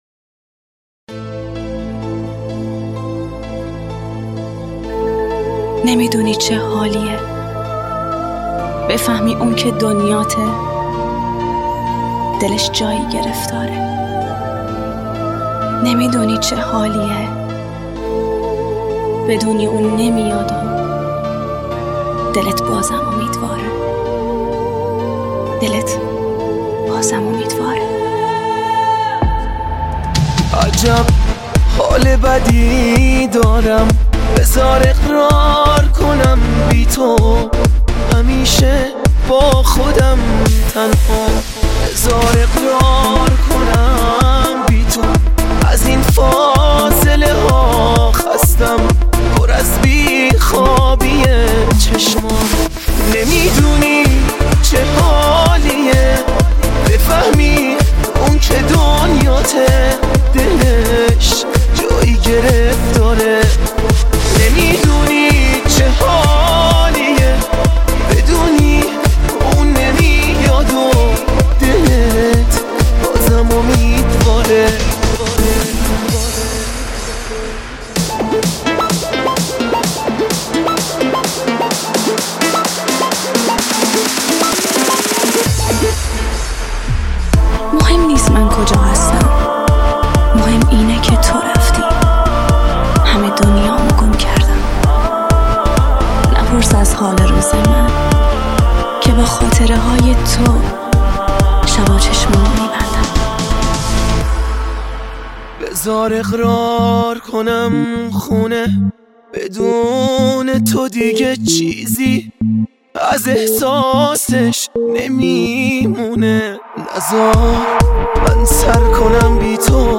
خواننده پاپ